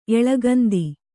♪ eḷagandi